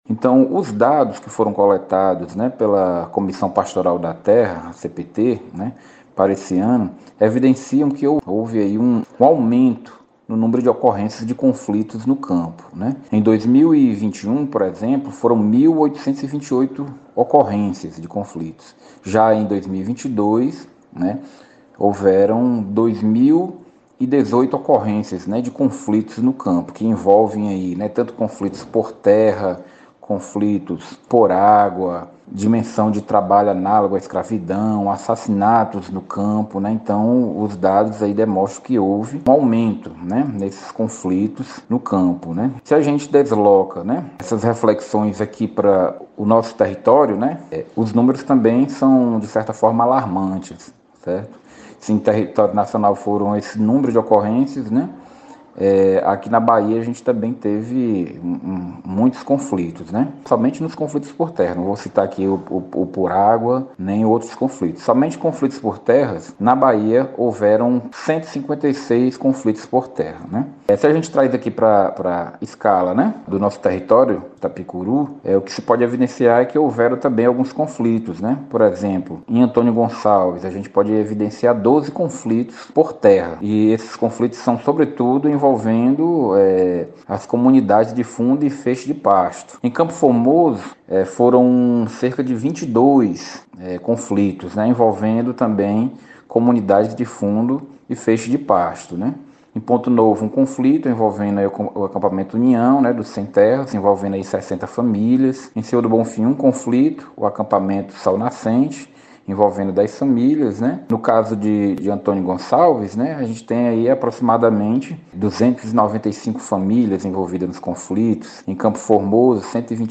Em entrevista ao Jornal das Sete da rádio 98 FM nesta sexta-feira (05)